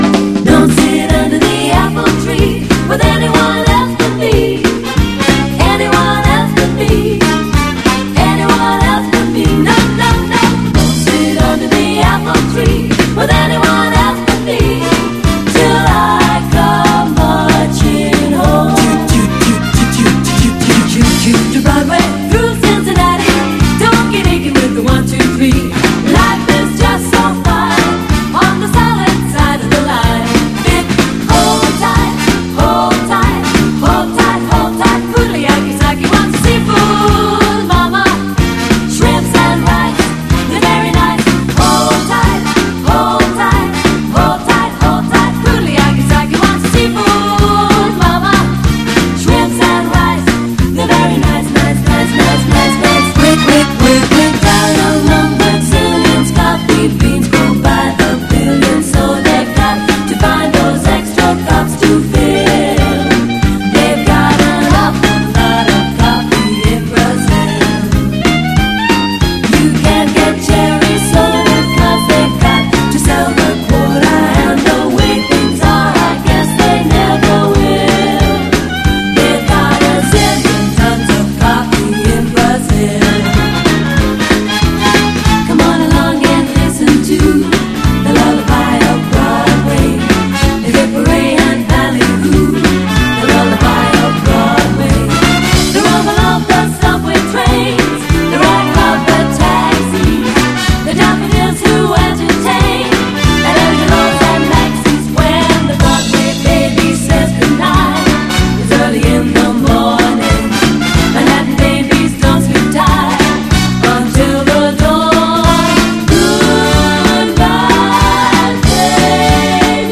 HIP HOP/R&B / BREAKBEATS / MEGA-MIX / OLD SCHOOL / DISCO RAP